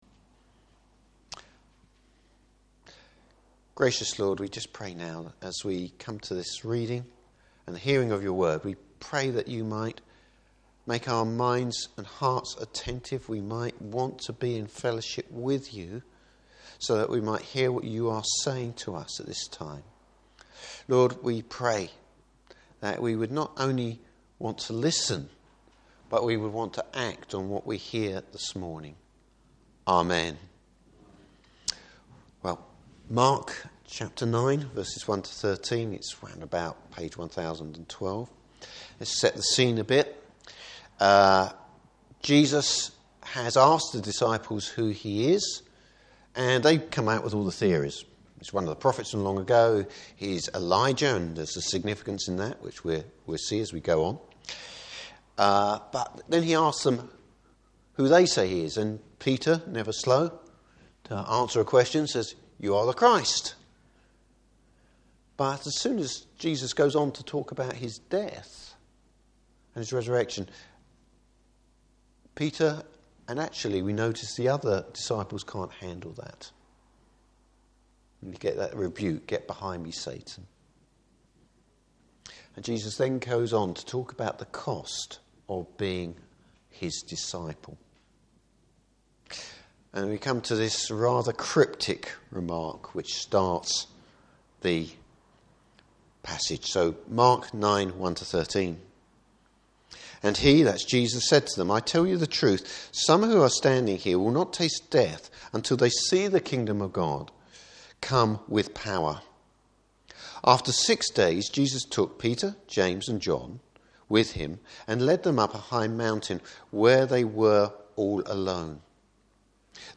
Service Type: Morning Service How does the Kingdom of God come?